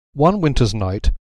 Below are some examples; notice, in the cases where one is preceded by other words, that the pitch steps up onto the word one: